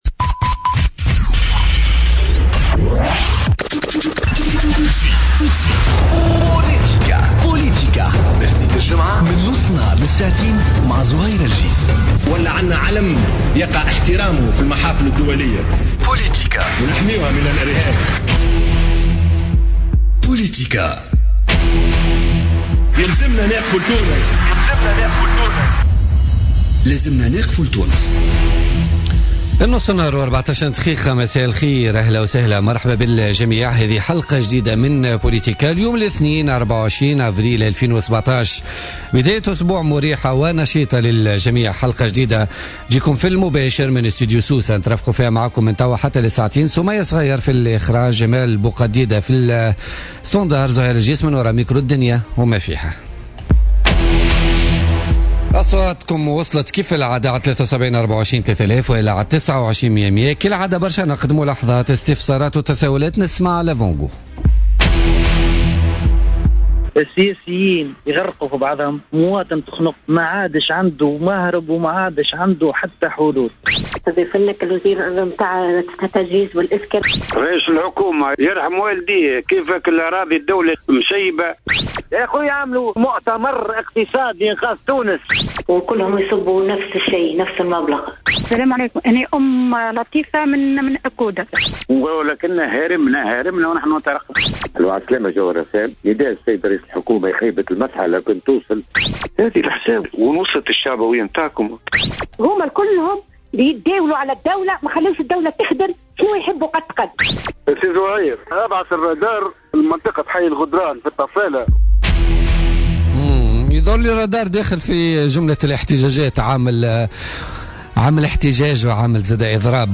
Monji El Rahoui, invité de Politica